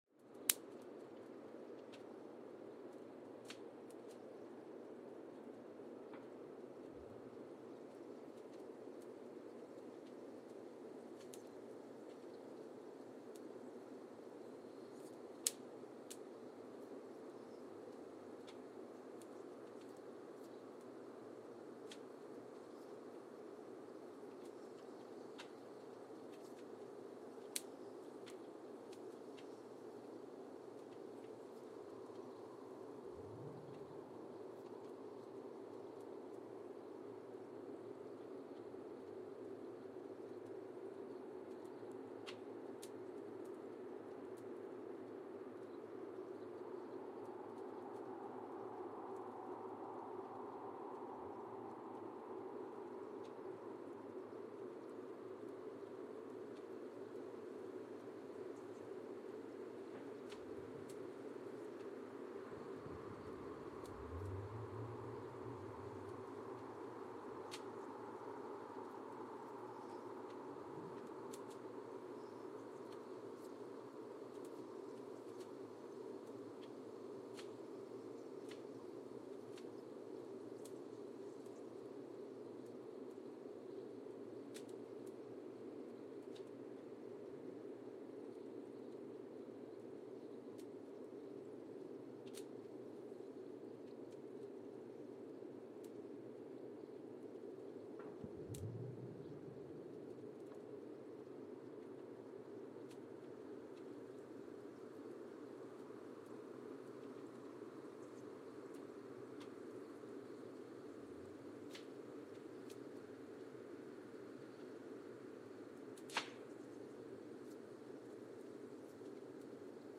Mbarara, Uganda (seismic) archived on February 24, 2018
Sensor : Geotech KS54000 triaxial broadband borehole seismometer
Speedup : ×1,800 (transposed up about 11 octaves)
Loop duration (audio) : 05:36 (stereo)